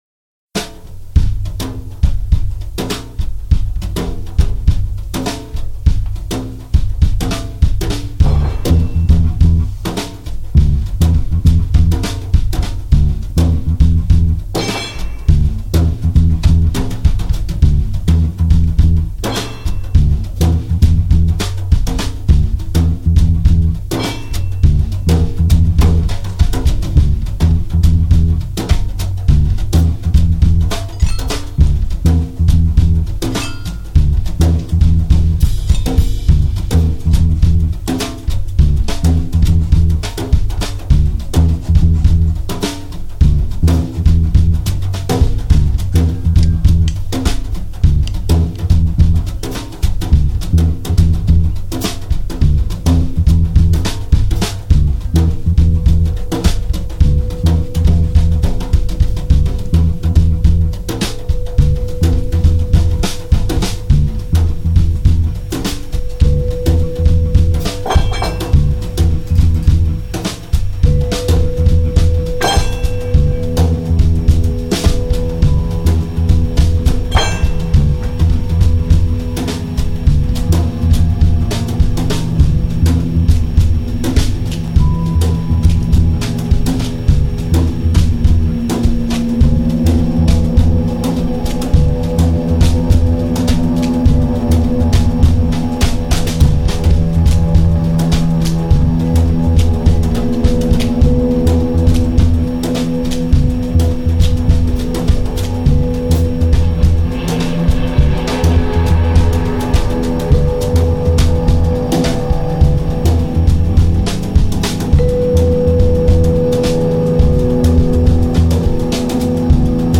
experimental folk music